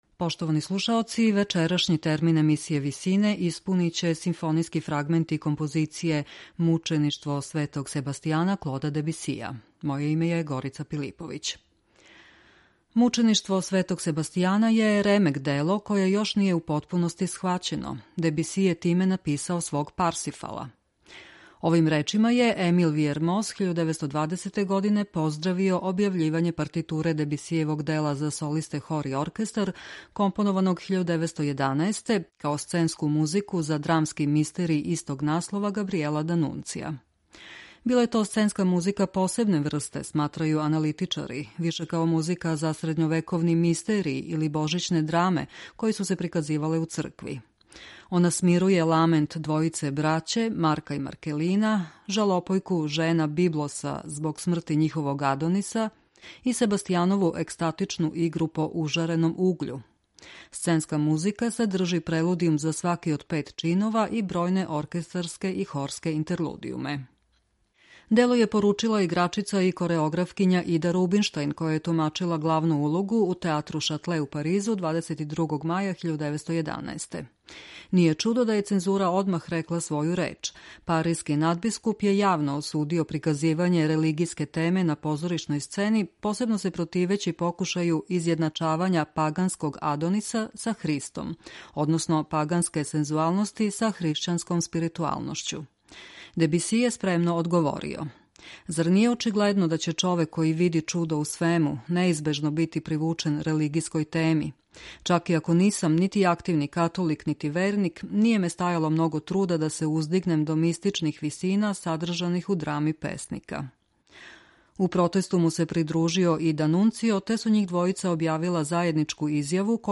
Вечерашњи термин емисије Висине испуниће симфонијски фрагменти композиције „Мучеништво Св.
Овим речима је Емил Вијермос 1920. године поздравио објављивање партитуре Дебисијевог дела за солисте, хор и оркестар, компонованог 1911, као сценску музику за драмски мистериј истог наслова Габријела Д'Анунција.